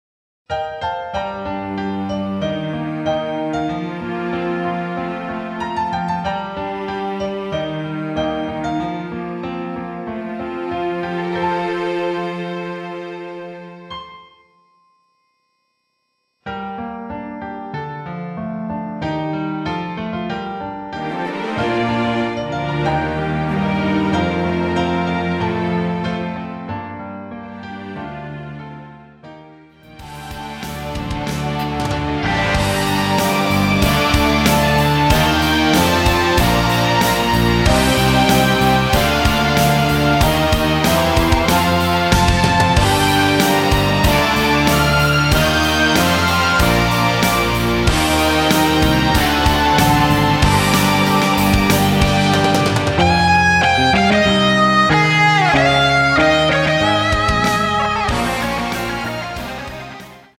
MR입니다.
Gm
앞부분30초, 뒷부분30초씩 편집해서 올려 드리고 있습니다.
중간에 음이 끈어지고 다시 나오는 이유는